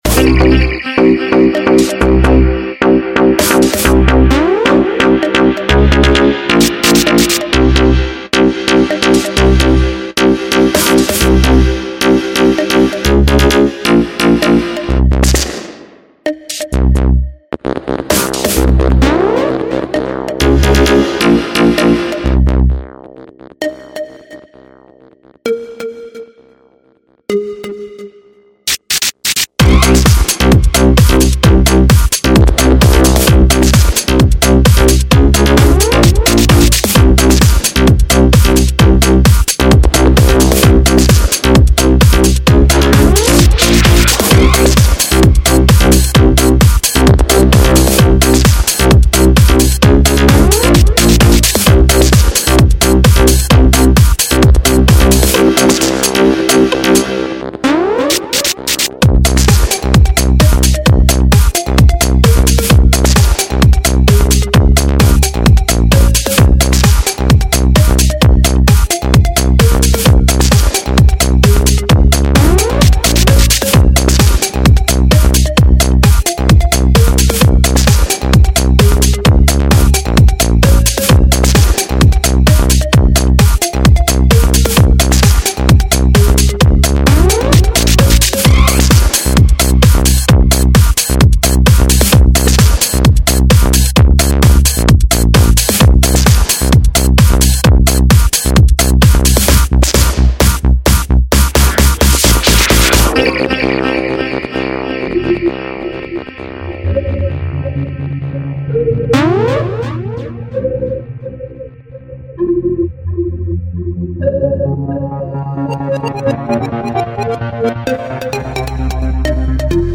Стиль:Electro Progressive